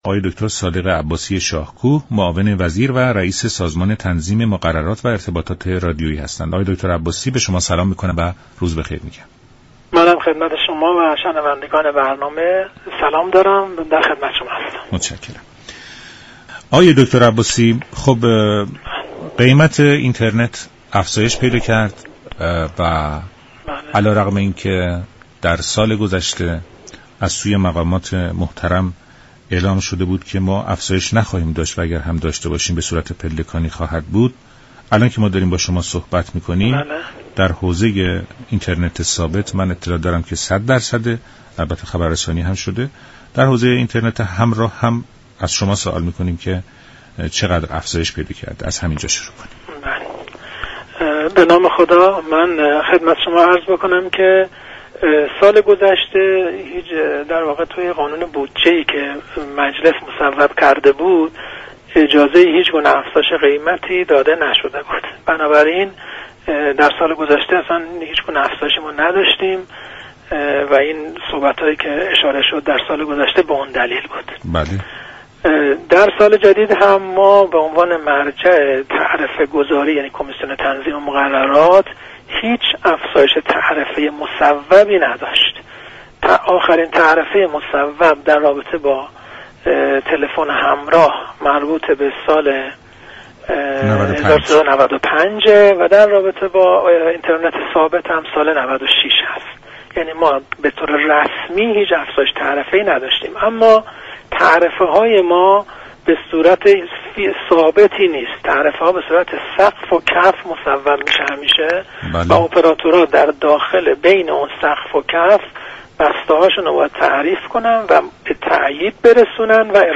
به گزارش شبكه رادیویی ایران، دكتر صادق عباسی شاهكو معاون وزیر و رئیس سازمان تنظیم مقررات و ارتباطات رادیویی در برنامه «ایران امروز» درباره تعرفه های اینترنت خانگی و تلفن همراه گفت: در قانون بودجه 1400 مصوبه مجلس، به دلیل آنكه اجازه هیچگونه افزایش قیمتی داده نشده بود، هیچ تغییری در قیمت ها به وجود نیامد.